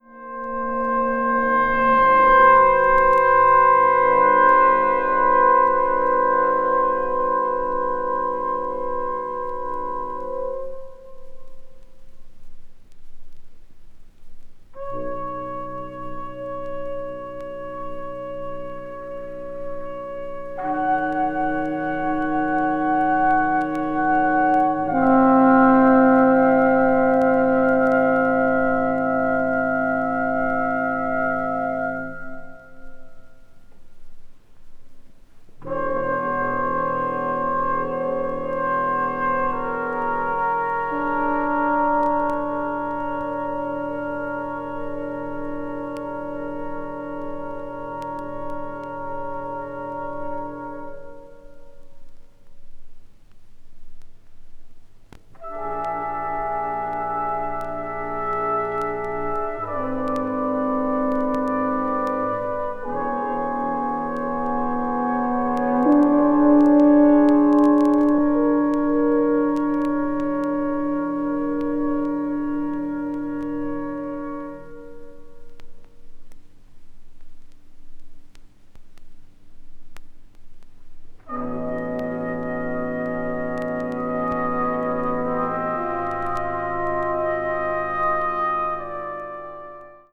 20th century   chamber music   contemporary   post modern